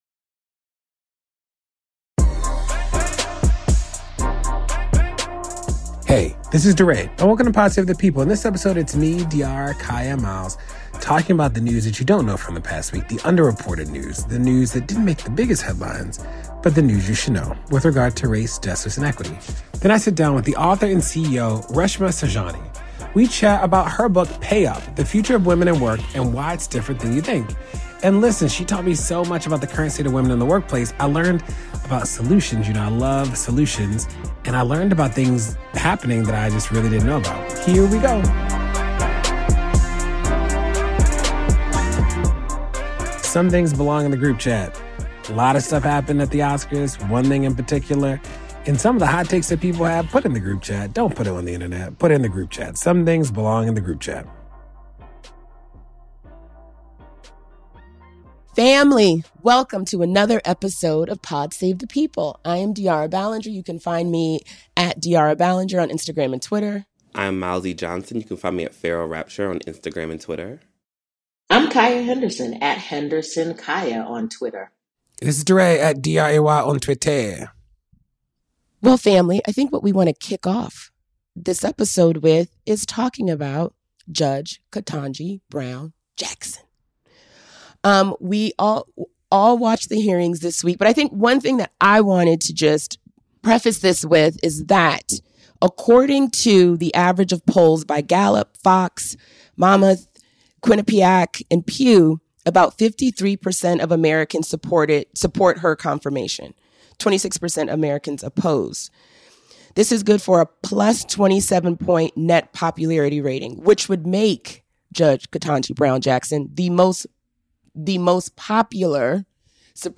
DeRay interviews author and activist Reshma Saujani about her new book Pay Up: The Future of Women and Work (and Why It's Different Than You Think).